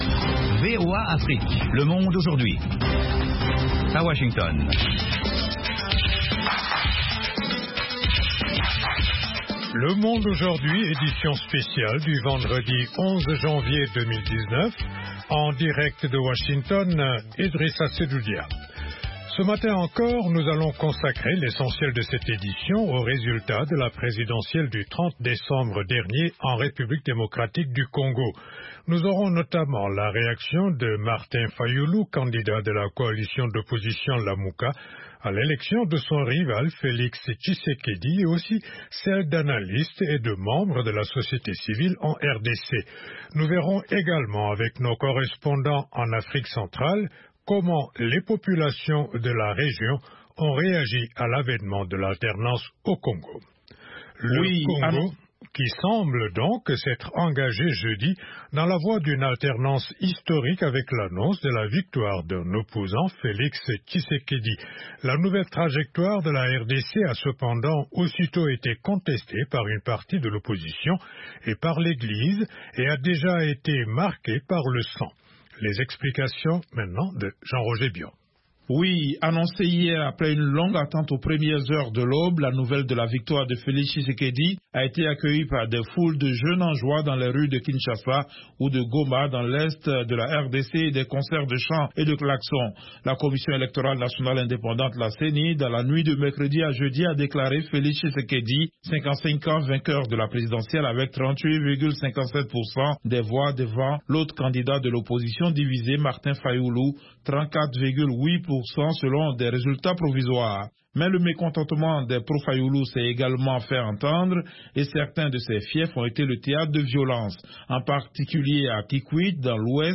Interviews, reportages de nos envoyés spéciaux et de nos correspondants, dossiers, débats avec les principaux acteurs de la vie politique et de la société civile. Aujourd'hui l'Afrique Centrale vous offre du lundi au vendredi une synthèse des principaux développementsdans la région.